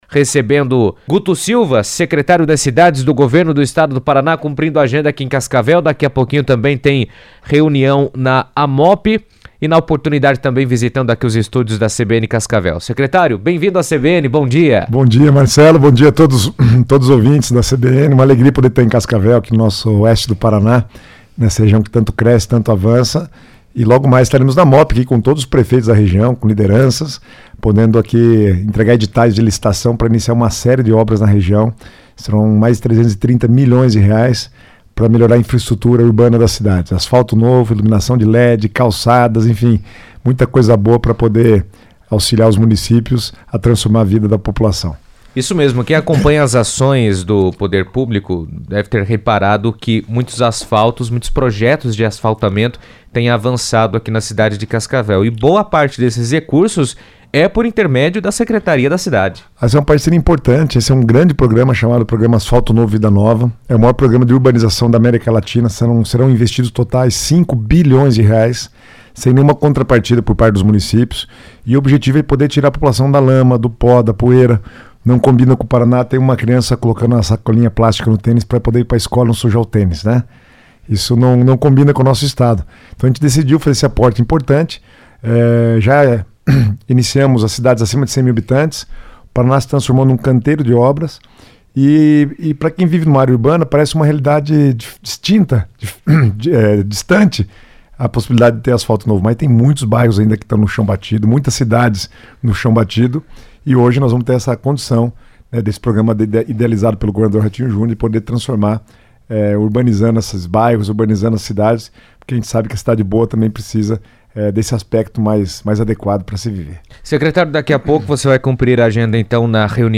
O secretário das Cidades do Governo do Estado, Guto Silva, esteve na CBN Cascavel para falar sobre os principais projetos e programas desenvolvidos pela secretaria, além de compartilhar as expectativas e metas para 2026.